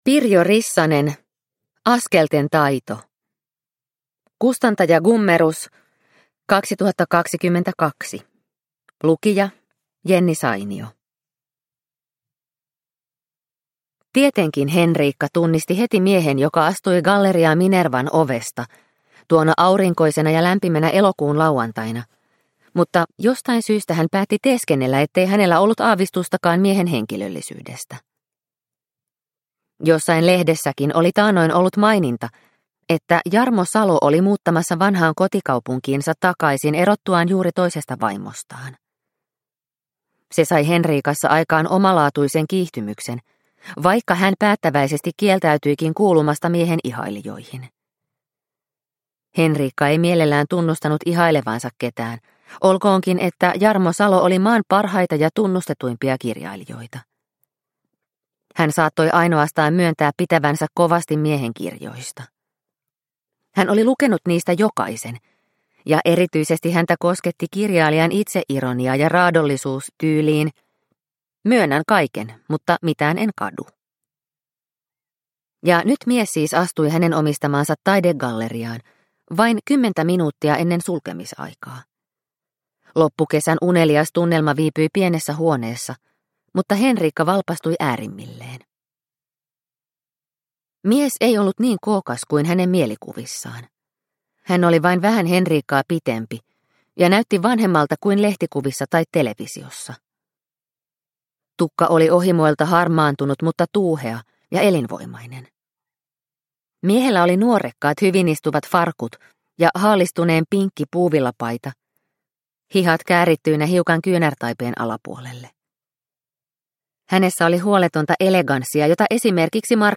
Askelten taito – Ljudbok – Laddas ner